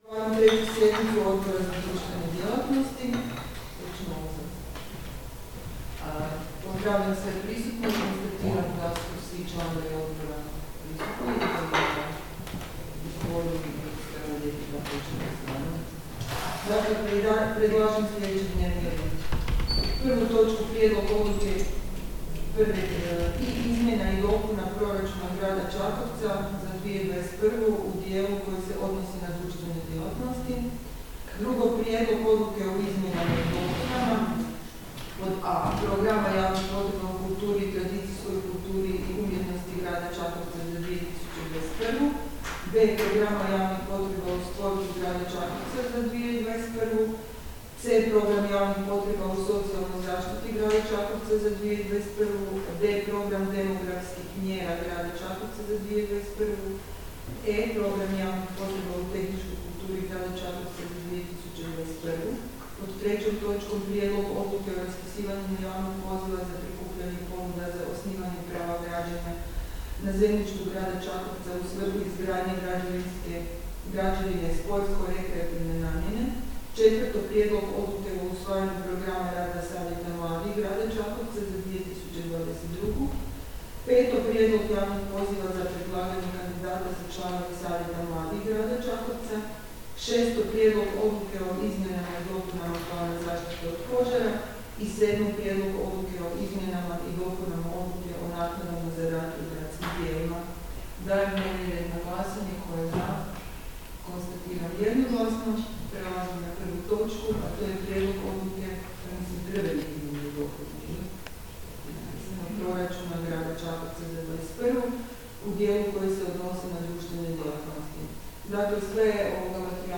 Obavještavam Vas da će se 3. sjednica Odbora za društvene djelatnosti Gradskog vijeća Grada Čakovca održati 18. listopada 2021. (ponedjeljak), u 08.00 sati, u Upravi Grada Čakovca, gradskoj vijećnici.